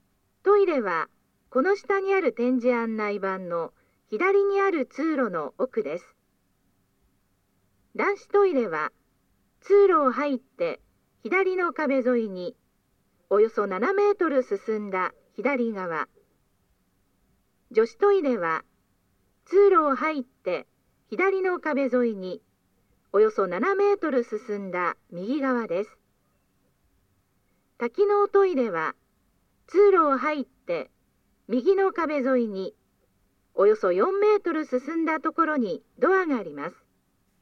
スピーカー種類 TOA天井型
トイレ案内1
音声は、トイレ案内です。